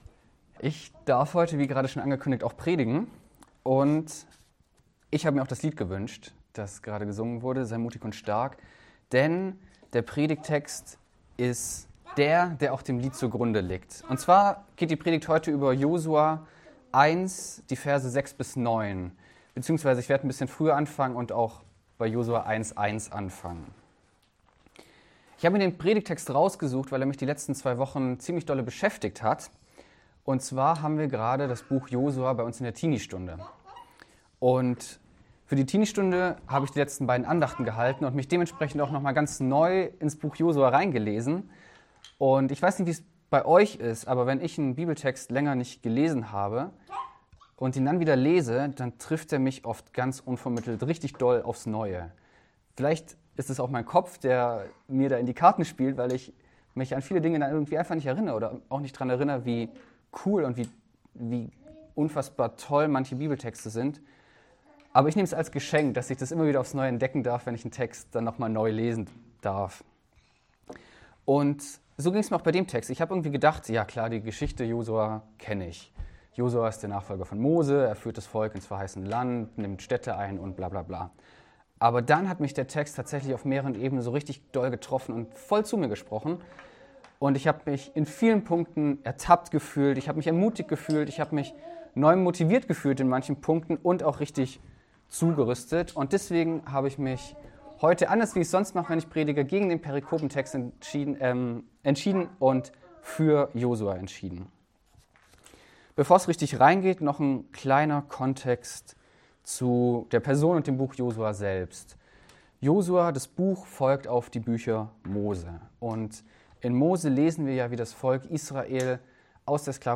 Eine predigt aus der serie "GreifBar+."